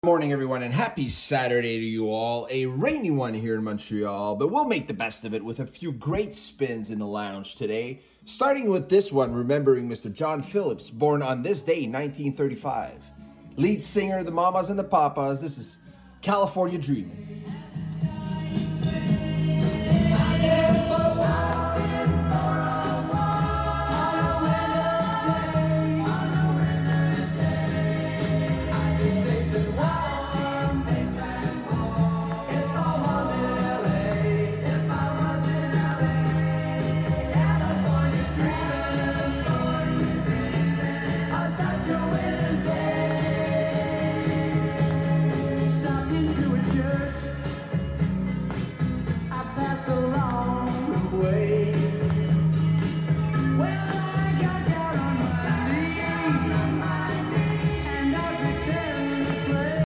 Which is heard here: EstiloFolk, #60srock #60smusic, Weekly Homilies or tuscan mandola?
#60srock #60smusic